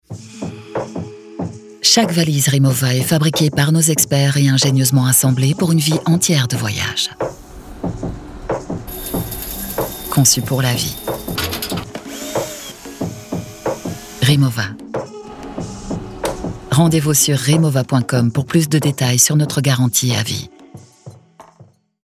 Voz en off en Francés
Cálida, Profundo, Seguro, Maduro, Empresarial
Comercial